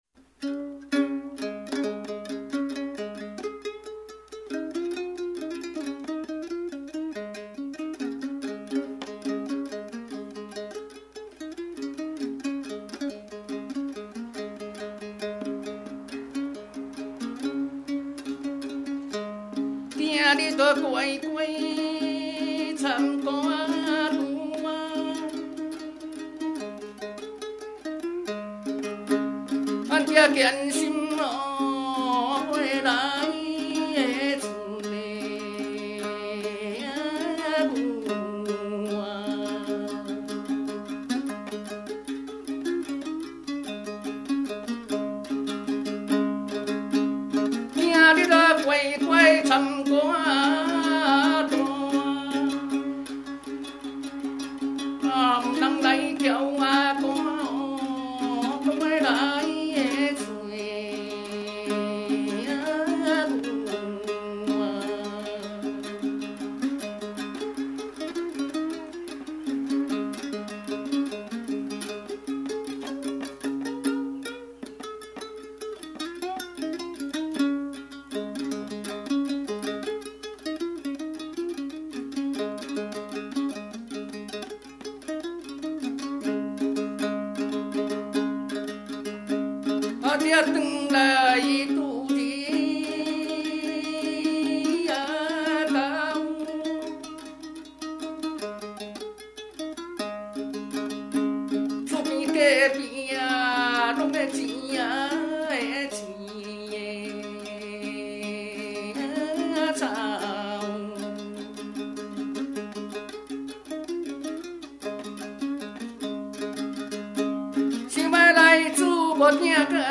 ◎制作群 ： 演出：月琴,壳仔弦｜
台湾民歌